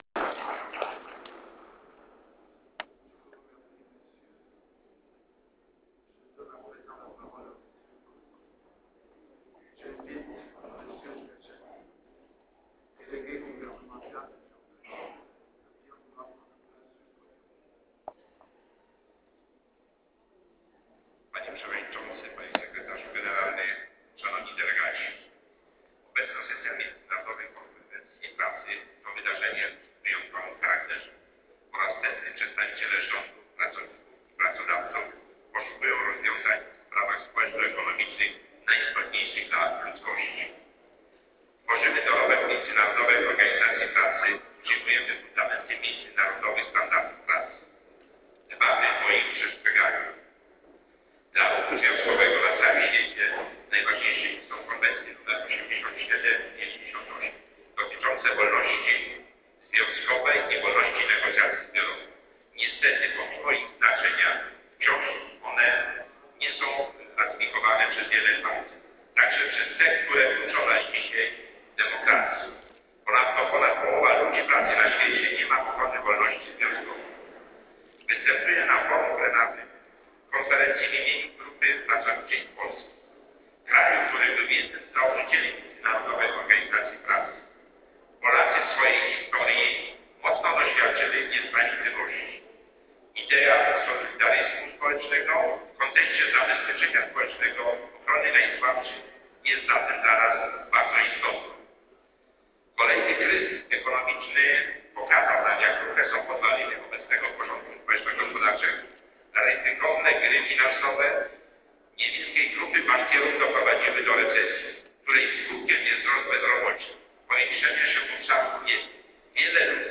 • wystąpienie w czasie 100. Sesji MKP
wersja głosowa